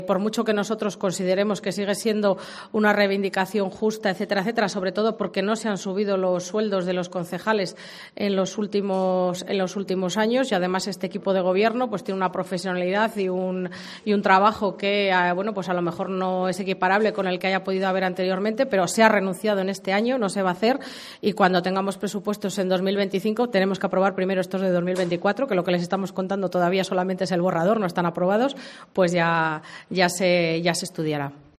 Rosalía Serrano, concejala de Hacienda, sobre las retribuciones del equipo de gobierno de Segovia